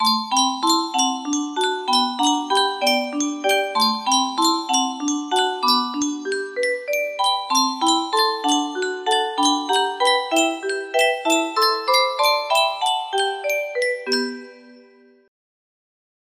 Yunsheng Music Box - Little Miss Muffet Y472 music box melody
Full range 60